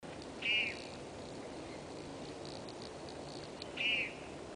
Suiriri Flycatcher (Suiriri suiriri)
Life Stage: Adult
Location or protected area: Reserva Natural del Pilar
Condition: Wild
Certainty: Recorded vocal